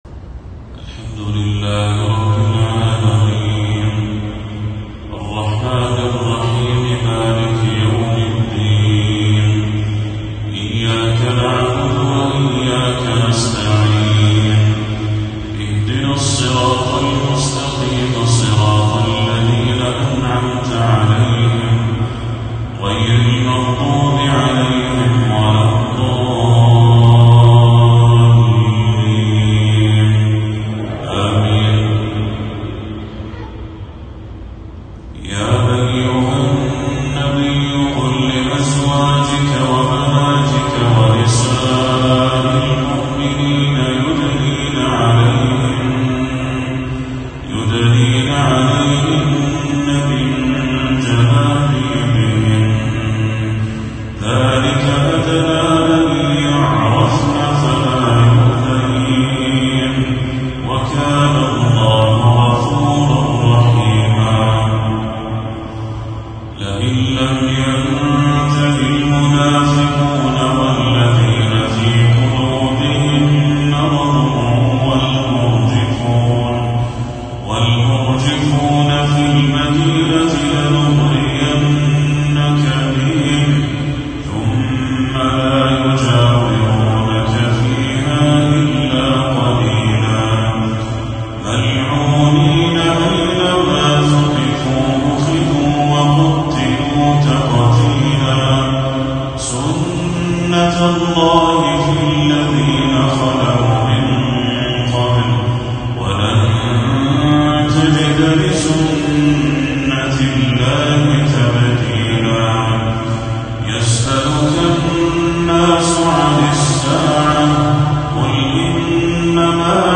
تلاوة جميلة لخواتيم سورة الأحزاب للشيخ بدر التركي | عشاء 24 صفر 1446هـ > 1446هـ > تلاوات الشيخ بدر التركي > المزيد - تلاوات الحرمين